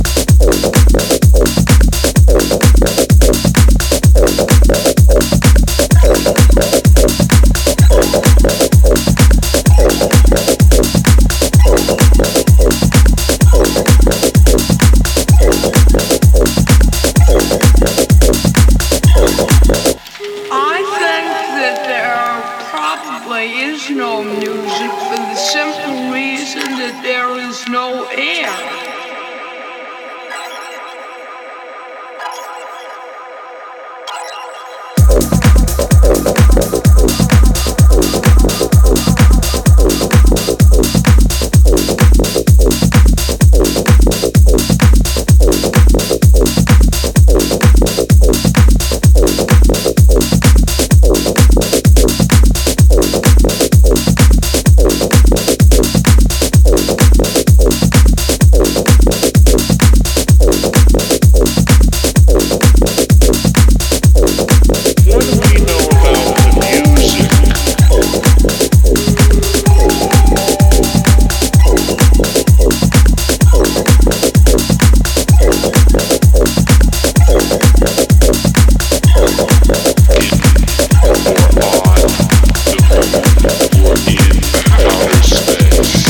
House tracks
edges on the more electronic side